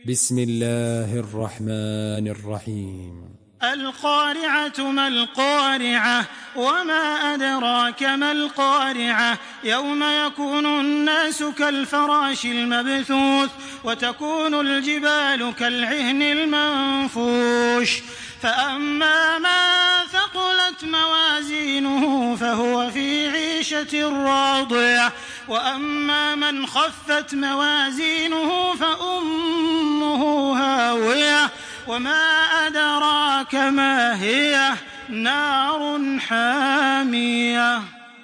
Surah আল-ক্বারি‘আহ্ MP3 by Makkah Taraweeh 1426 in Hafs An Asim narration.
Murattal